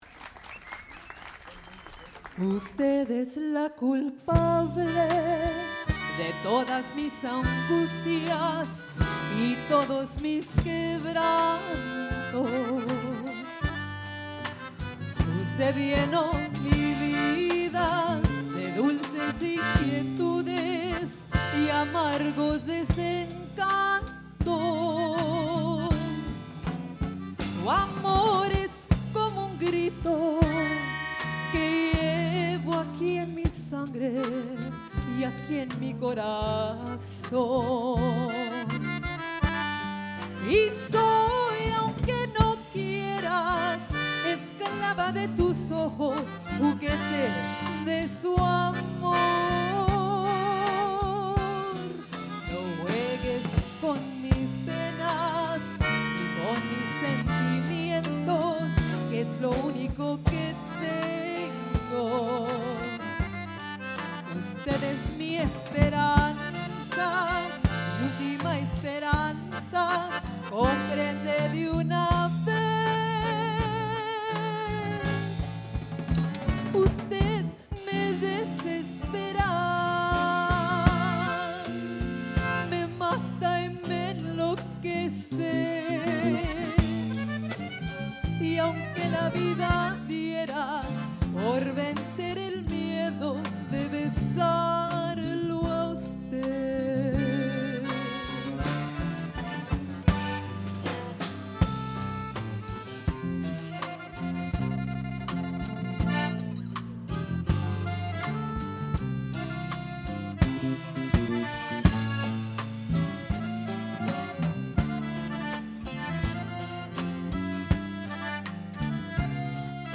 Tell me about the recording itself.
A variety of music genres was recorded at a concert in Granger in October 1993.